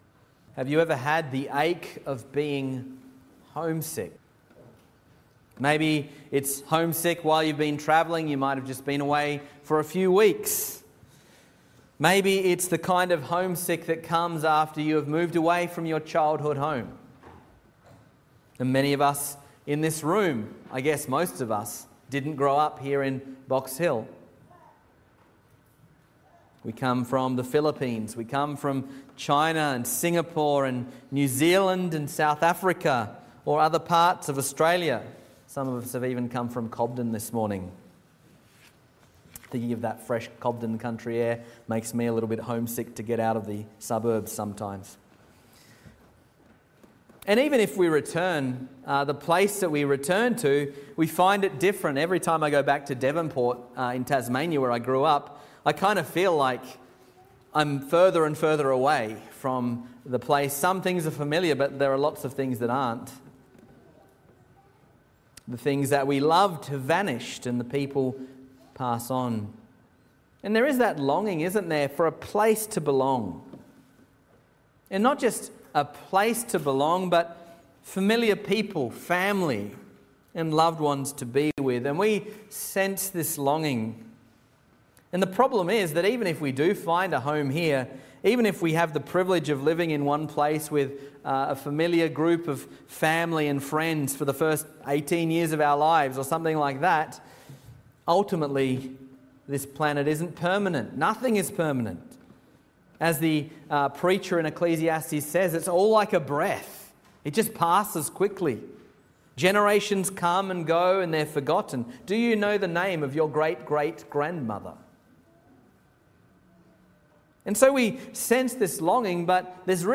Sermons | Reformed Church Of Box Hill
Morning Service